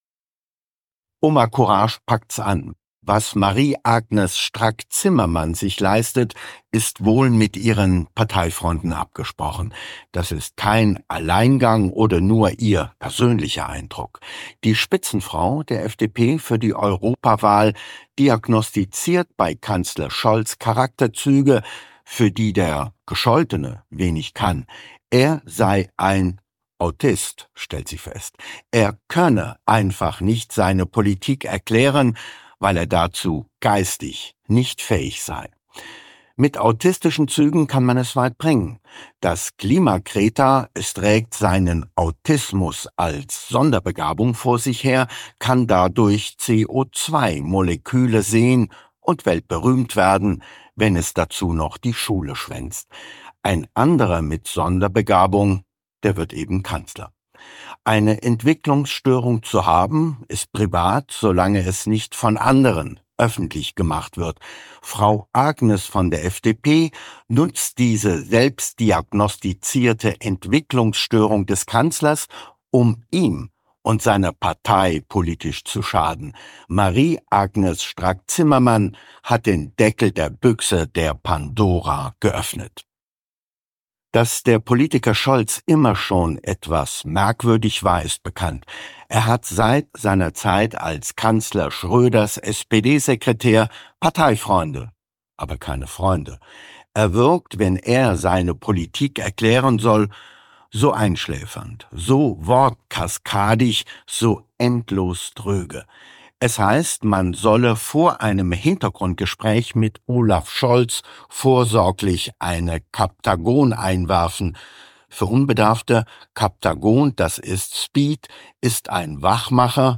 Kontrafunk-Kommentar